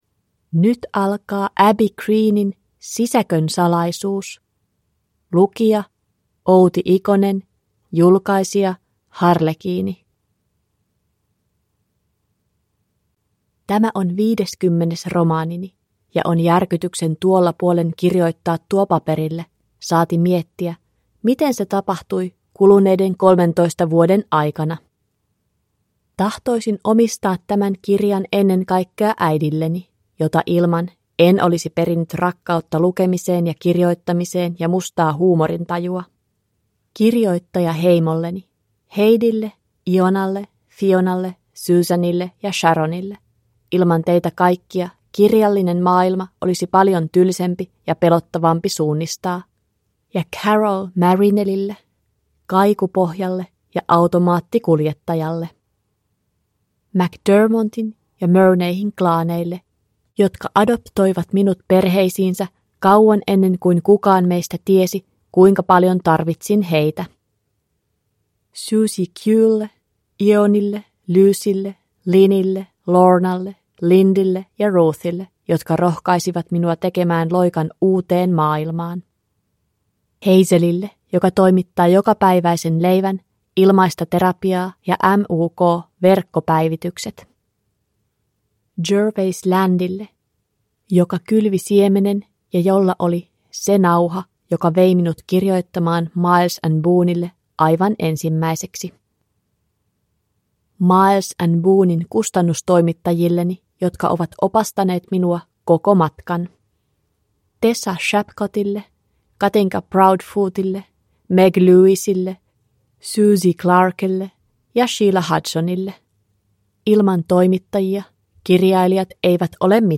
Sisäkön salaisuus – Ljudbok – Laddas ner